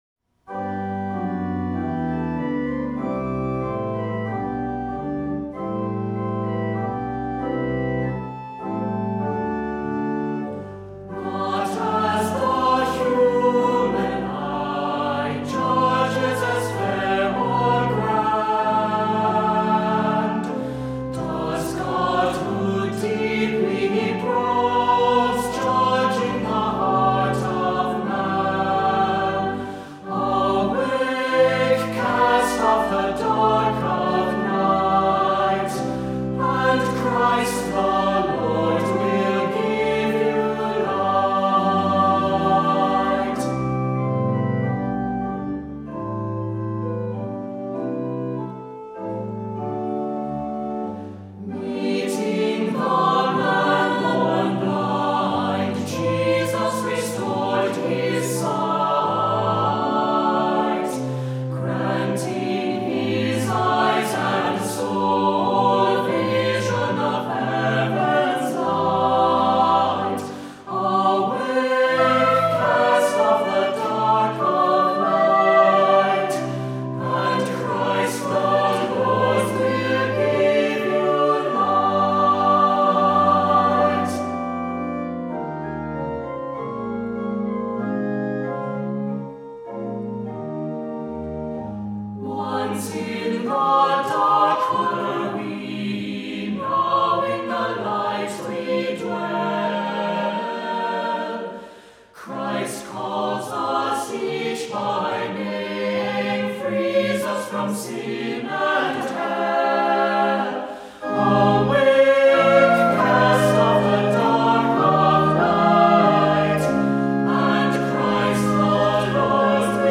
Voicing: Assembly,Descant,SATB